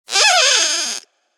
squeak.ogg